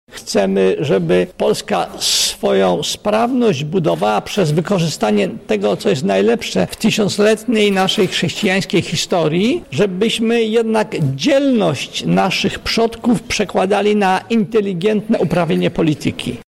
Doradcy prezydenta RP prof. Andrzej Zybertowicz podkreślał z kolei jak ważna jest edukacja patriotyczna od najmłodszych lat: